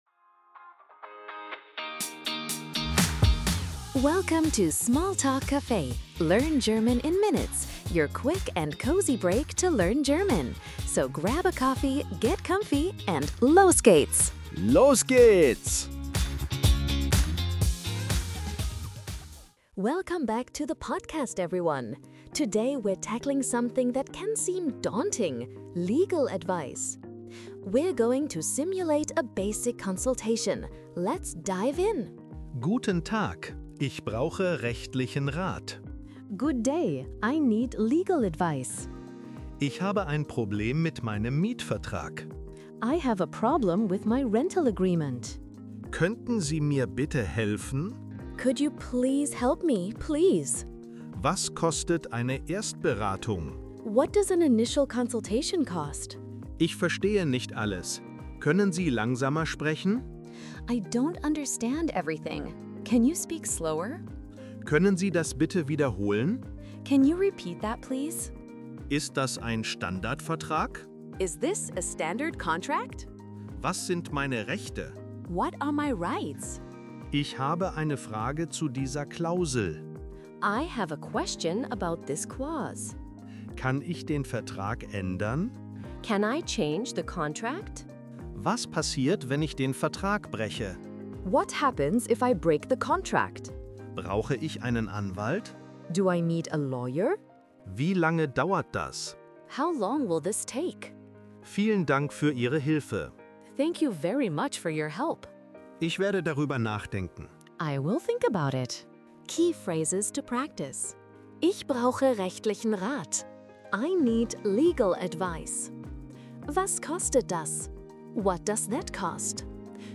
Tune in for quick, real-life dialogues, helpful tips, and the confidence boost you need to navig…